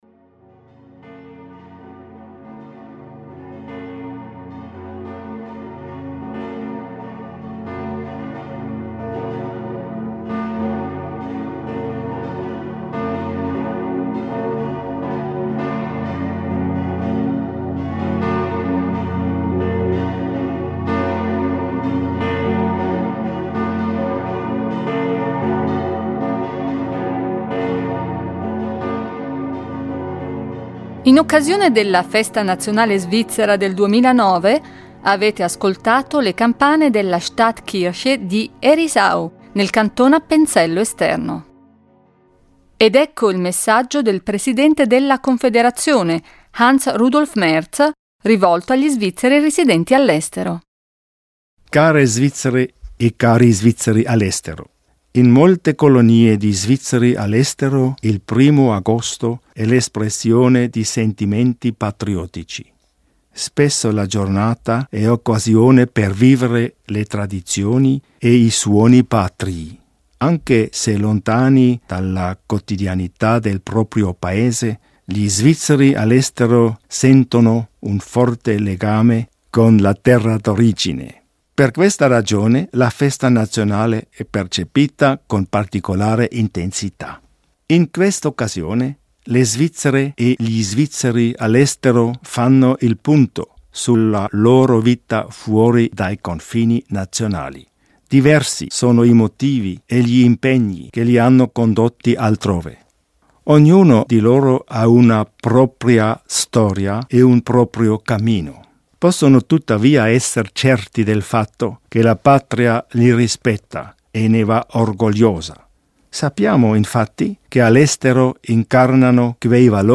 Il discorso del presidente della Confederazione Hans-Rudolf Merz in occasione della Festa nazionale del primo agosto.